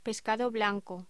Locución: Pescado blanco
voz